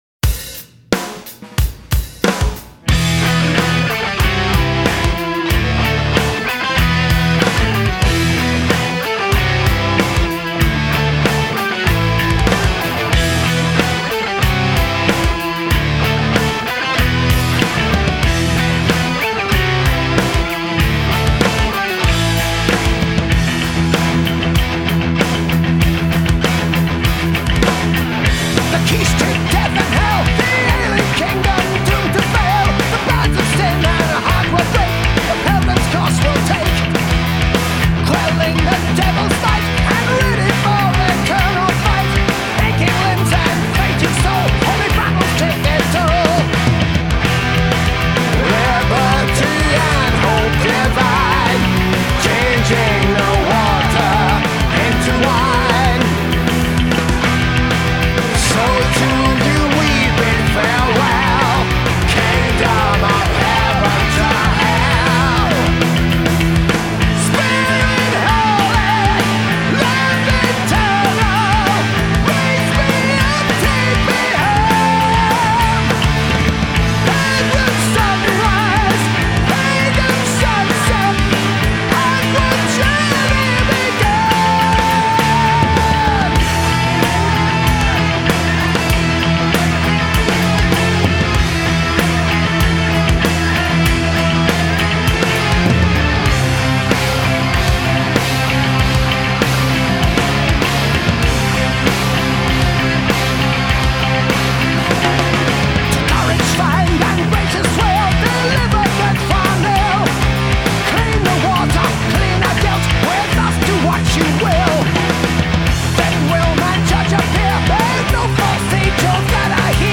рок-группы
Хэви метал Heavy Metal